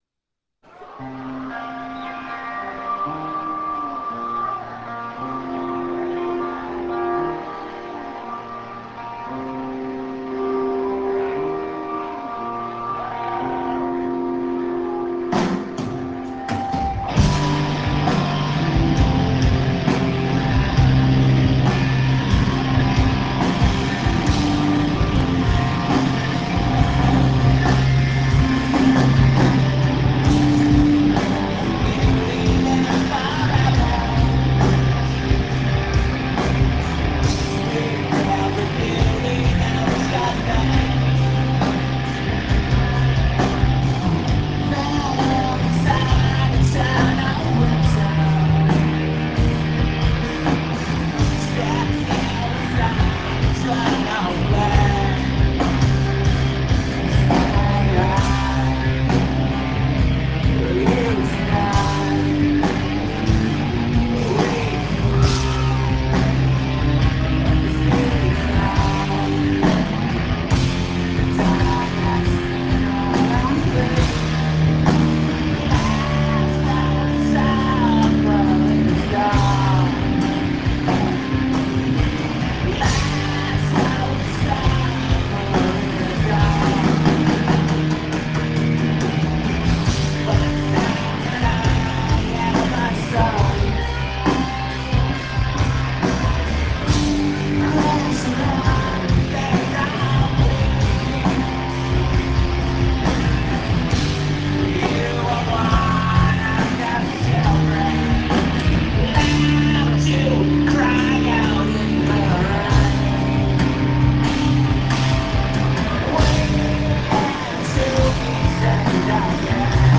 St. Andrew's Hall; Detroit, USA